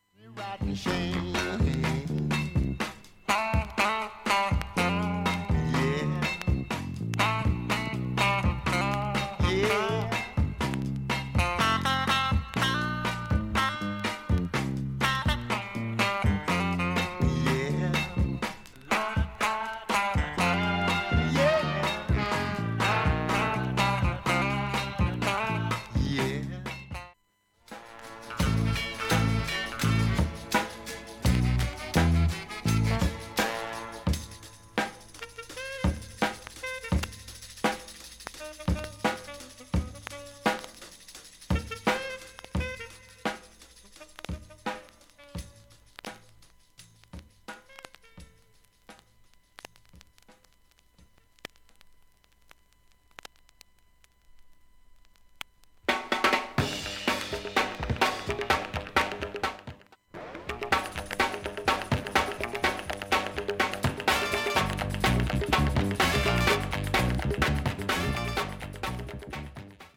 音質良好全曲試聴済み。
B-5始め聴こえないプツ3回出ます